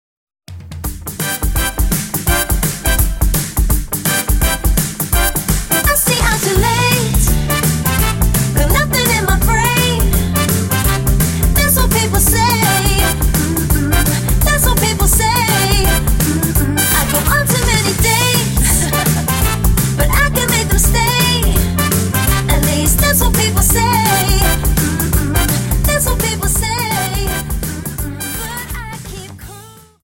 Dance: Jive Song